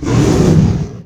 combat / creatures / dragon / he / hurt2.wav
hurt2.wav